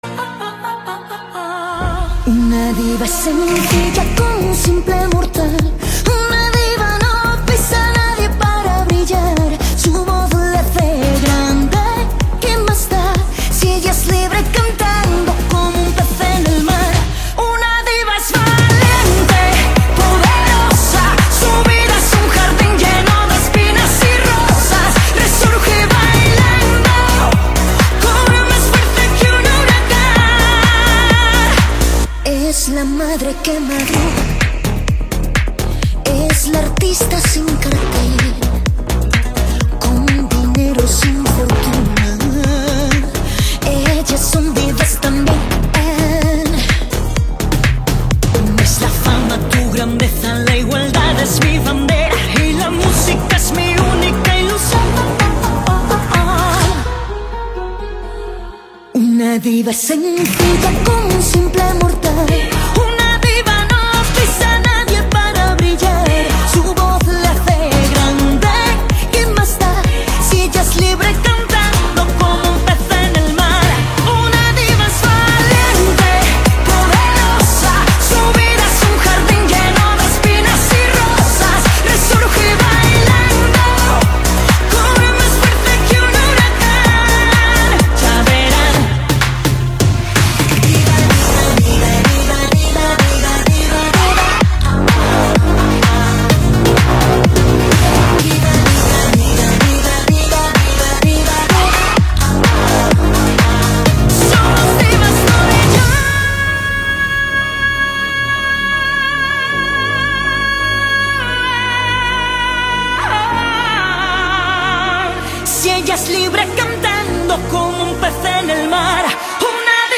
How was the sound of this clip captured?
Audio QualityCut From Video